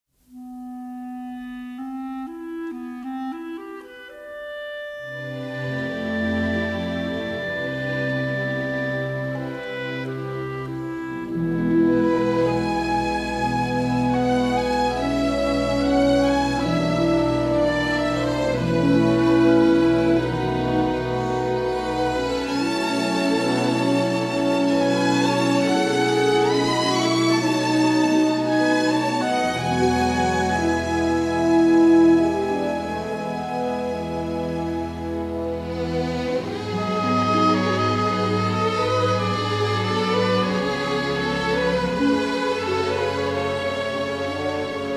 with notable use of harpsichord for sinister effect.
a soaring love theme